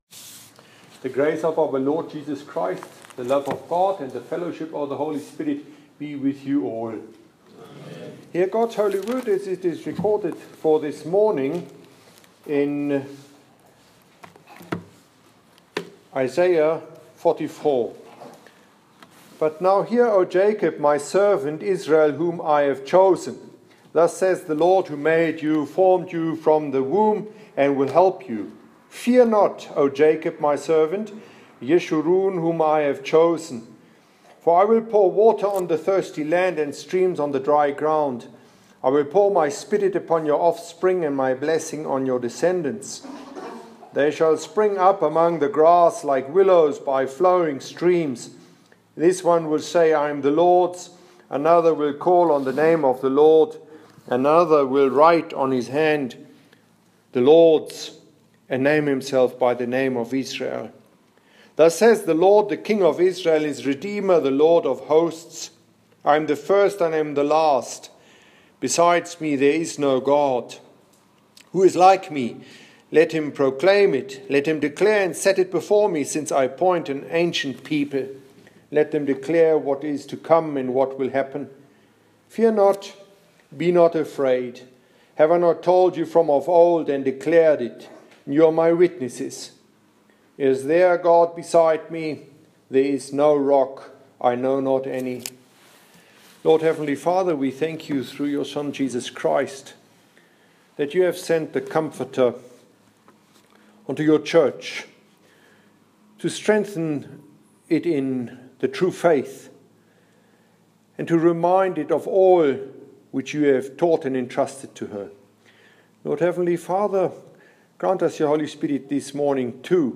This morning’s sermon at Matins was on Isaiah 44:1-8.